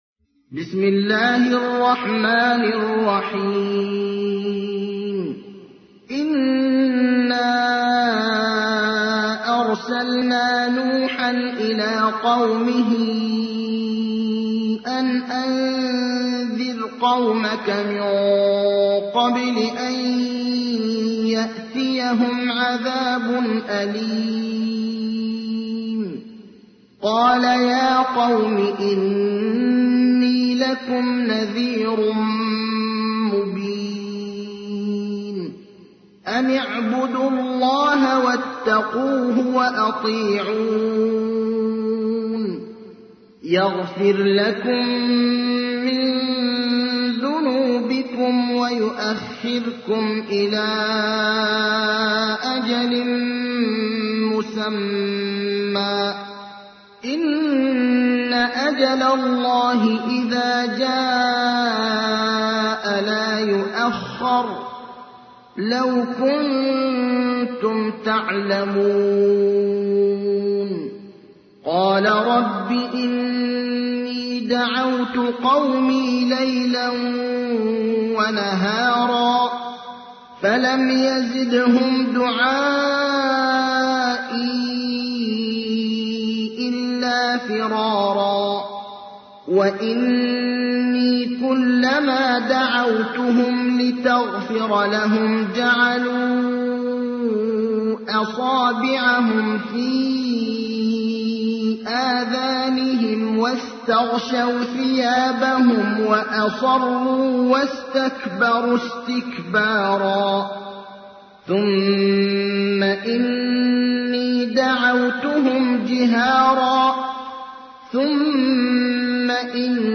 تحميل : 71. سورة نوح / القارئ ابراهيم الأخضر / القرآن الكريم / موقع يا حسين